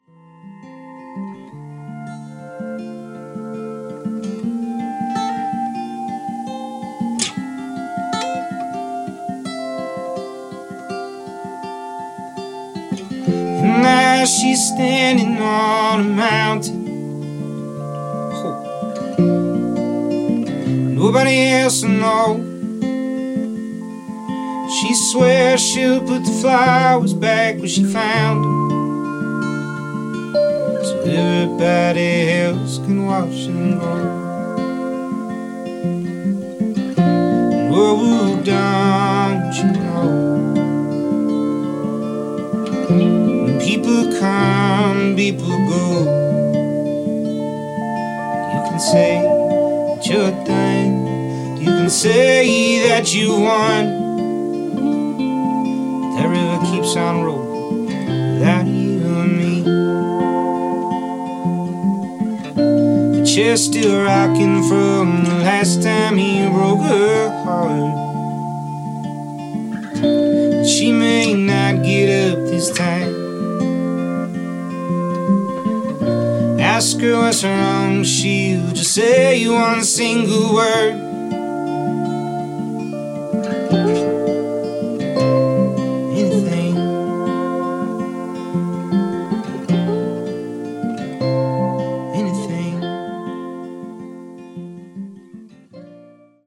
Americana, Folk